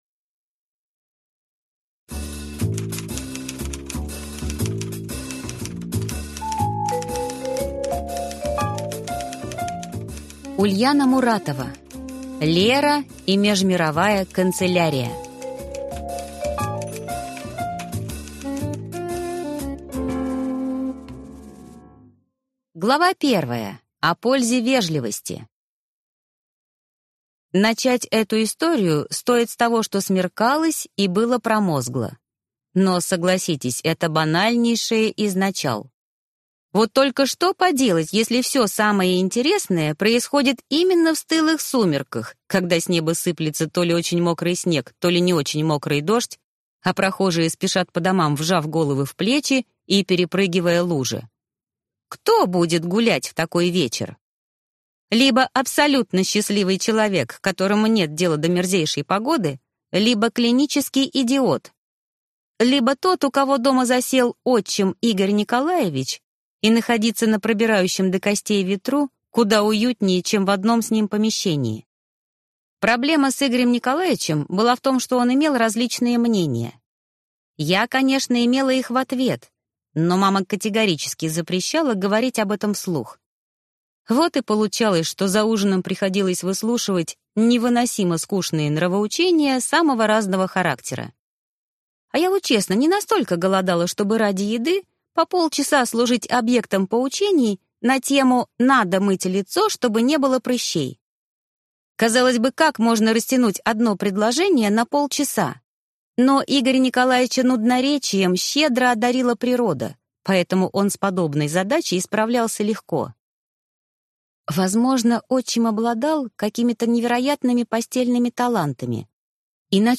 Релиз: Земля. Книга 1 (слушать аудиокнигу бесплатно) - автор Василий Маханенко